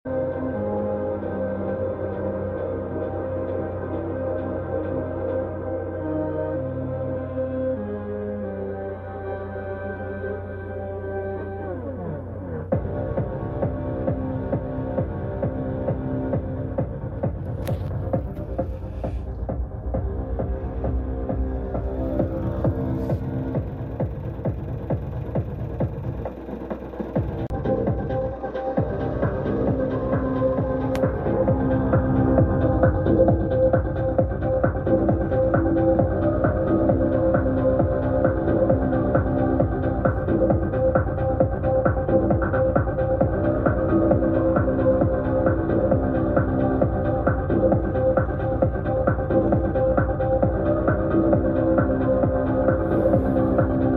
Musica mas Generador de Frecuencias sound effects free download